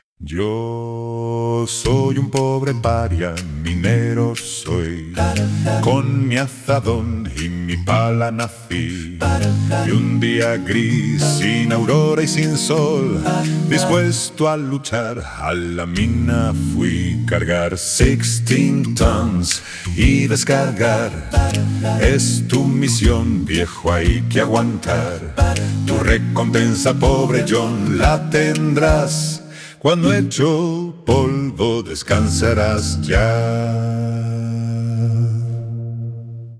Canciones de muestra con voces clonadas